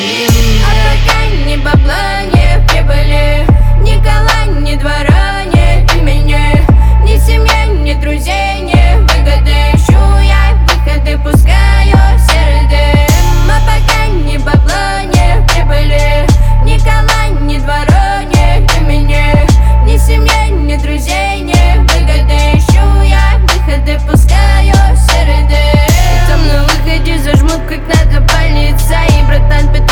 Жанр: Рэп и хип-хоп / Русские